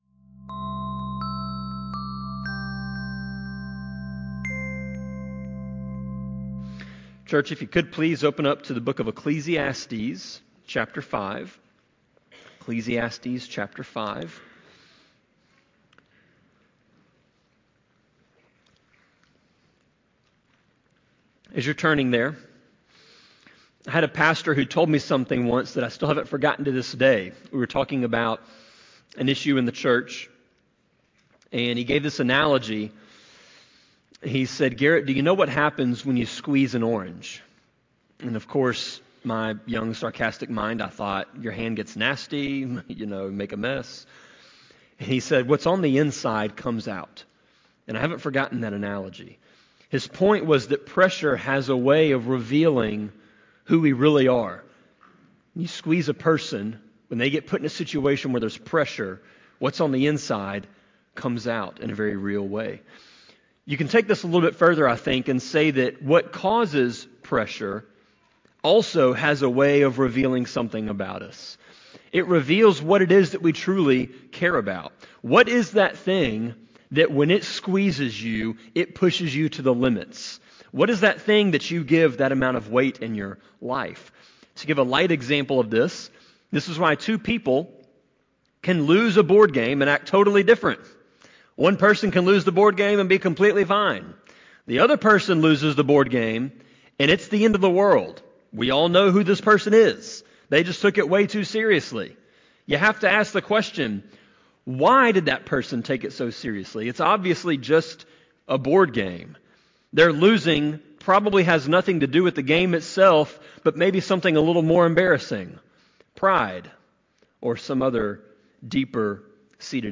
Sermon-25.3.16-CD.mp3